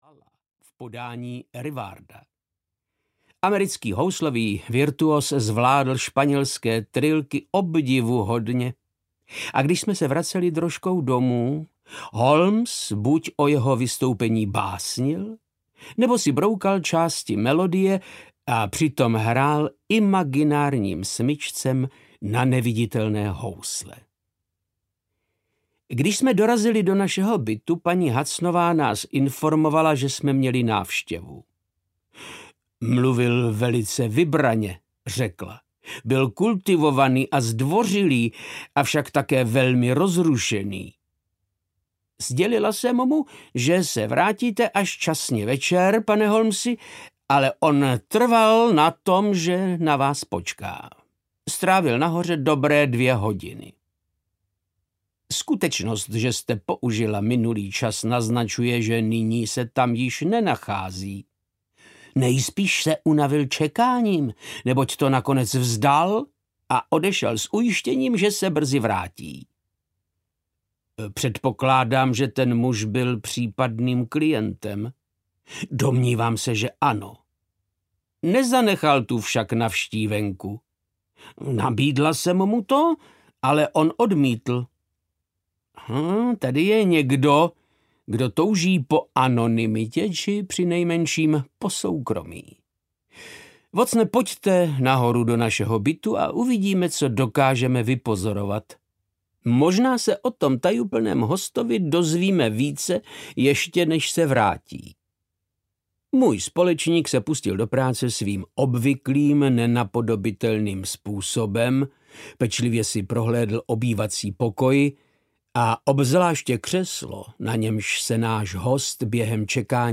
Sherlock Holmes a Labyrint smrti audiokniha
Ukázka z knihy
• InterpretVáclav Knop